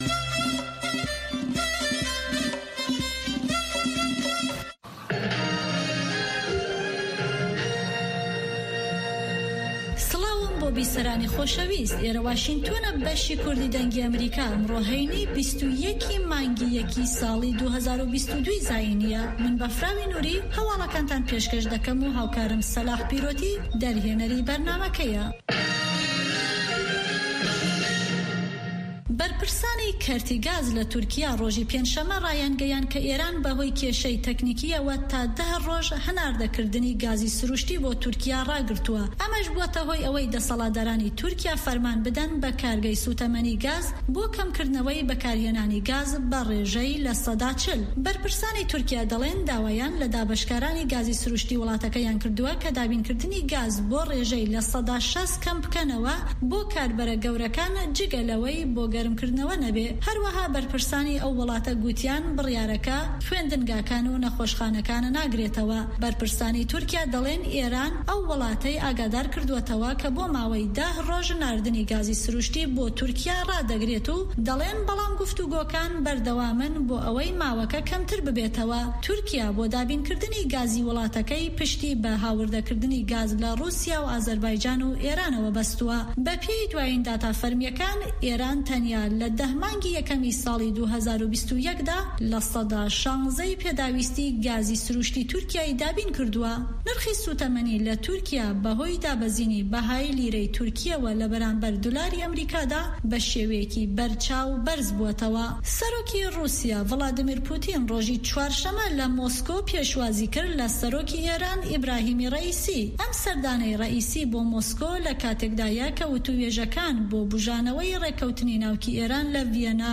هەواڵەکانی 1 ی شەو